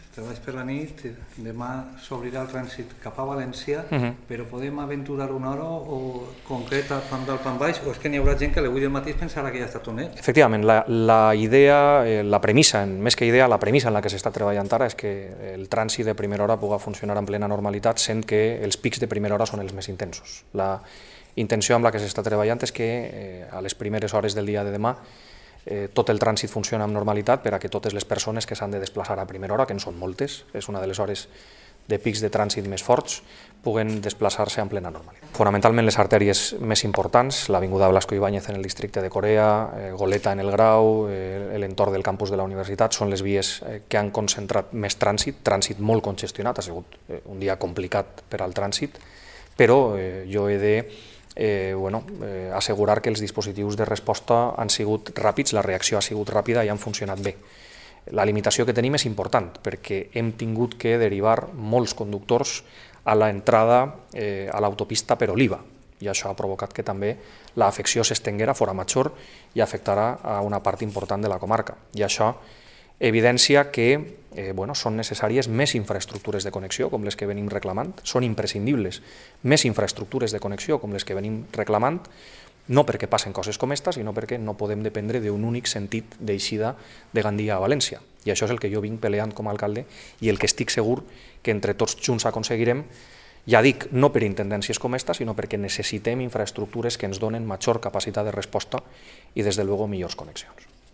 Escucha aquí al alcalde de Gandia, tras visitar esta mañana el lugar de los hechos y reunirse con técnicos, especialistas, Policía Local, Demarcación de Carreteras del Estado y Guardia Civil.
José Manuel Prieto ha comparecido pasadas la una y cuarto de la tarde de hoy ante los medios de comunicación para explicar los últimos trabajos llevados a cabo tras el accidente ocurrido esta madrugada, sobre la una, y del que COPE Gandia ha informado en primicia.